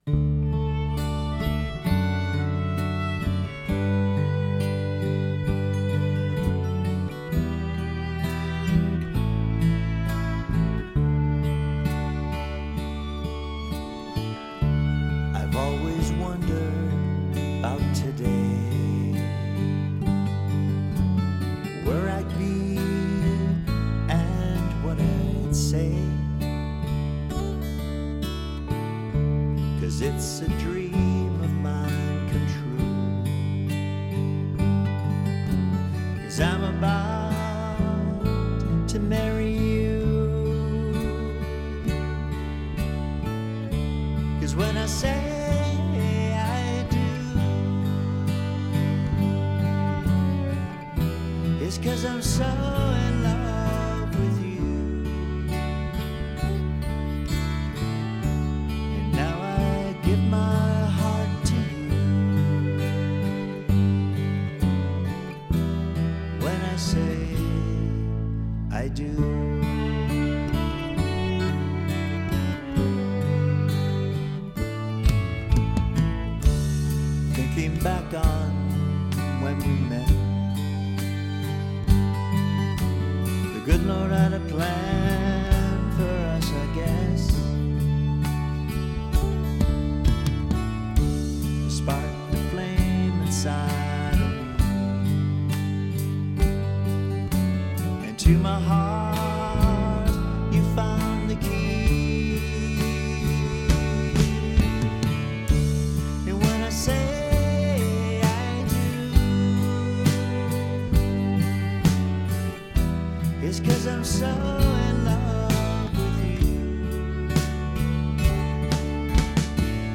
• ballads
Wedding Song | Sample Vocal: Male | Key: D